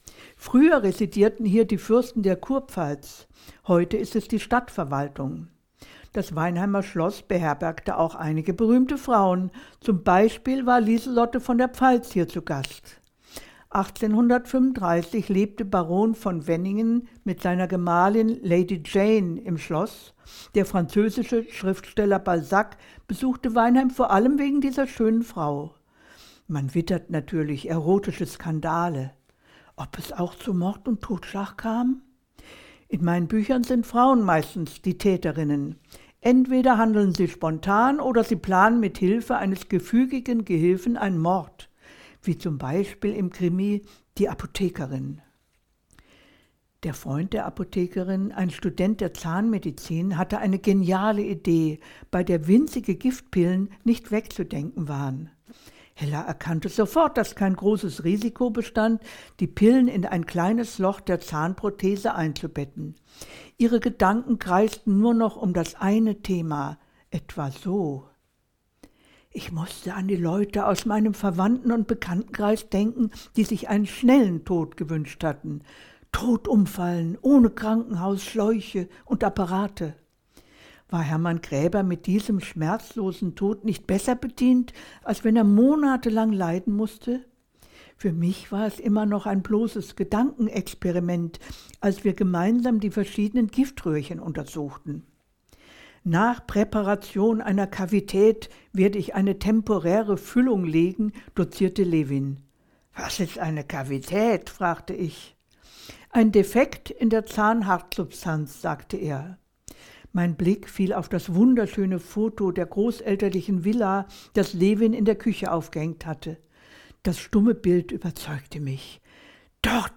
In ihrem ganz eigenen Stil und mit einem augenzwinkernd-subtilen Humor führt sie dann den Begleiter von Ort zu Ort.
Hier berichtet Ingrid Noll über die kurpfälzische Geschichte des Schlosses und ihre früheren schillernden Bewohner. Dazu liest sie eine Schlüsselszene aus ihrem Roman „Die Apothekerin“.